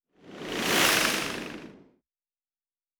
pgs/Assets/Audio/Sci-Fi Sounds/Movement/Fly By 04_2.wav at master
Fly By 04_2.wav